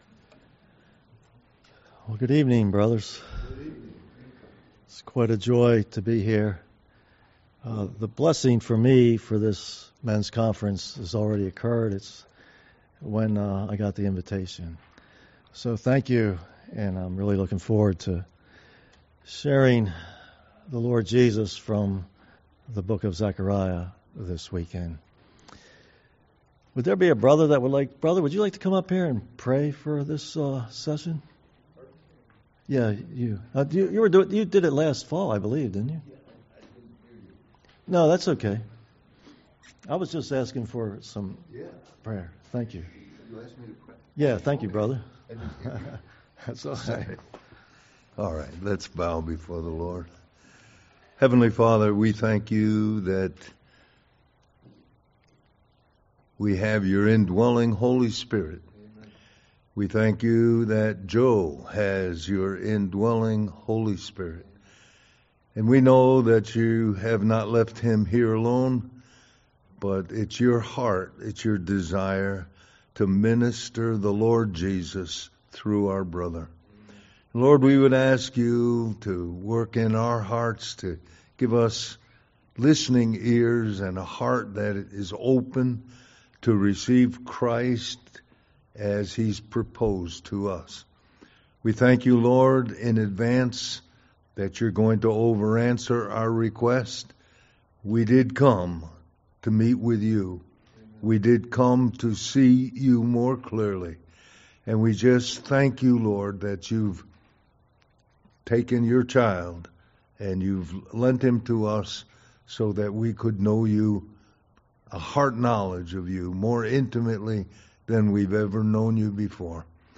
Zechariah's 8 Night Visions Conference: 2025 Spring Men's Weekend